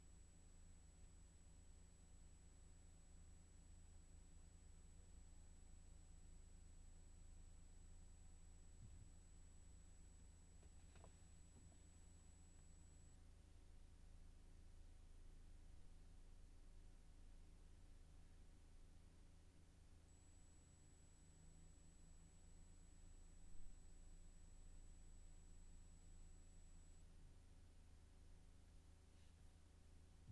High-pitched ringing noise
View attachment 24304 The noise is plainly audible to me. It's at ~7848 Hz and you can do your own FFT on my recording and look at the spectrum to verify. Headlights/window switch lights were on at the start. I turn them off at about 13.5 seconds, on again at 20 seconds, and off again at 27 seconds.